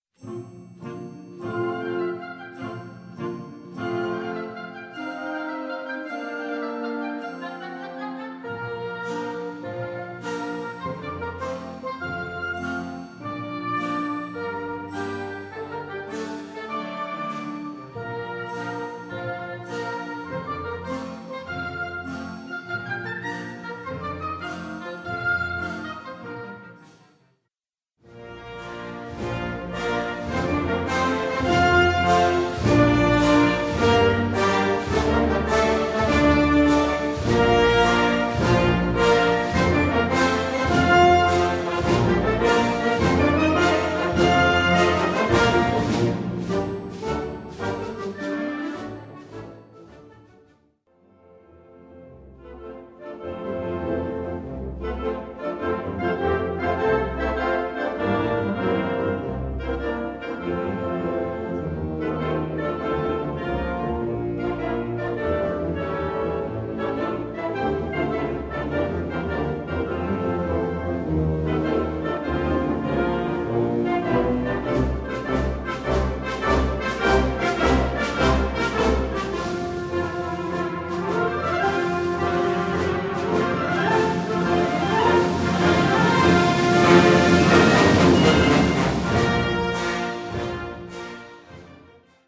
Konzertwerk für Blasorchester
Besetzung: Blasorchester